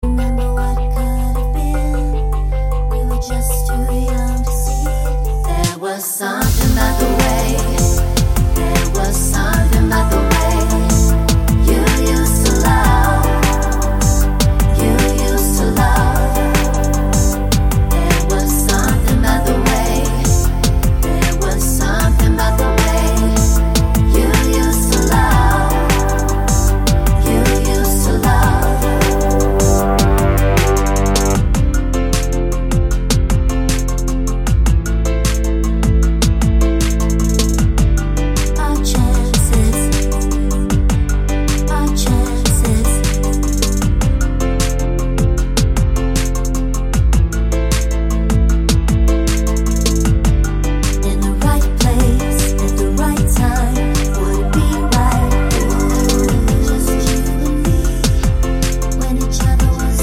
no Backing Vocals Pop (1990s) 4:36 Buy £1.50